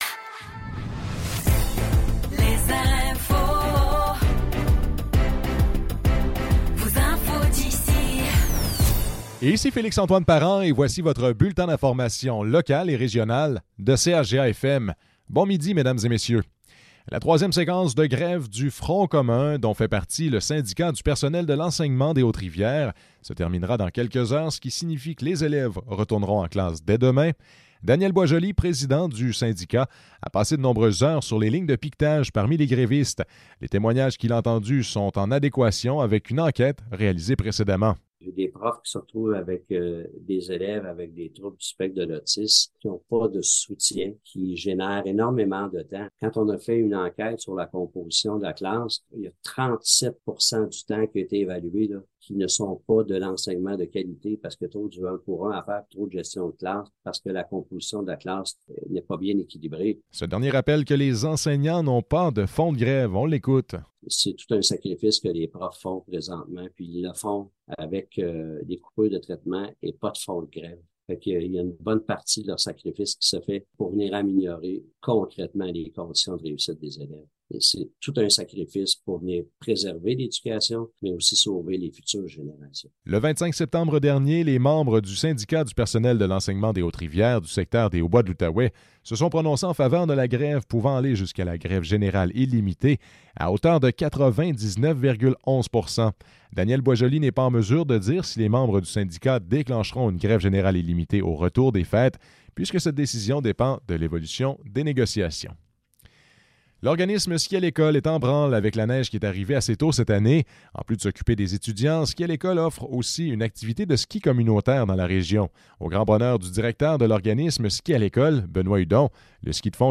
Nouvelles locales - 14 décembre 2023 - 12 h